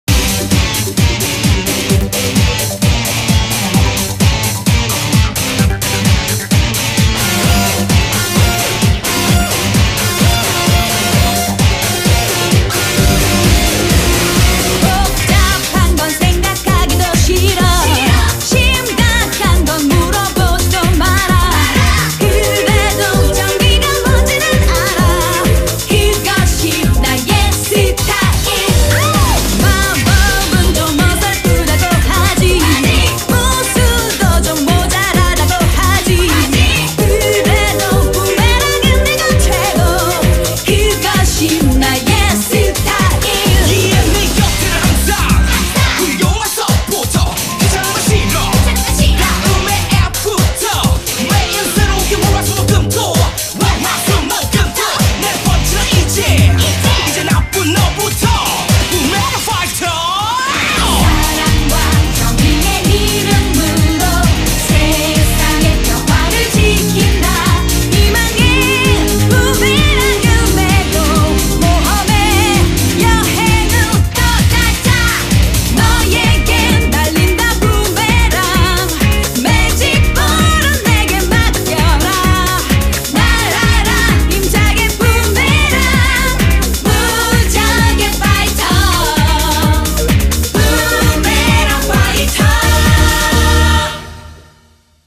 BPM130
Audio QualityCut From Video